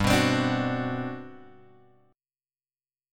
GM11 chord {3 2 x 5 3 2} chord